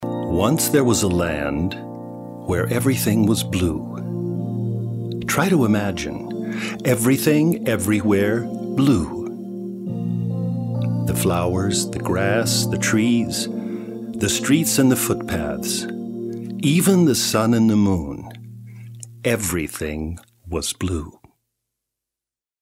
American Speaker, off speaker, advertizing, Internet, Industry, TV....Voice color: Middle to deep.
Sprechprobe: eLearning (Muttersprache):